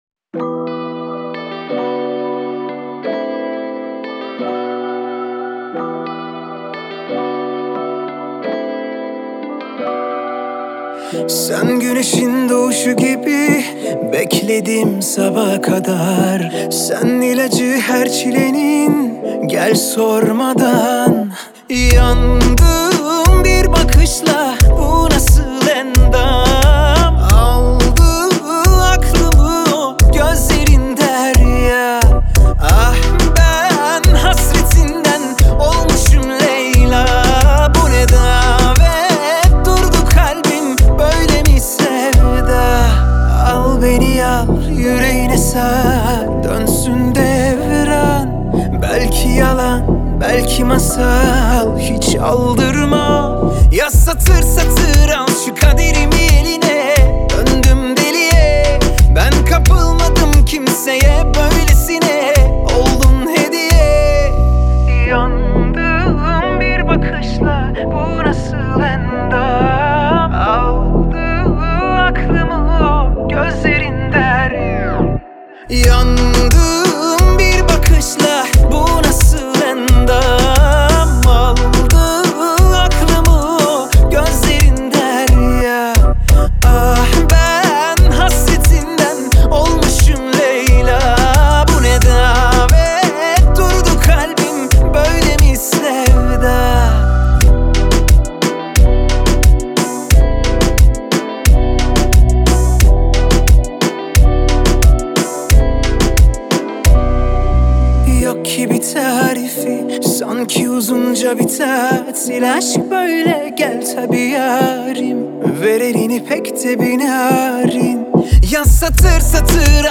آهنگ ترکیه ای آهنگ شاد ترکیه ای آهنگ هیت ترکیه ای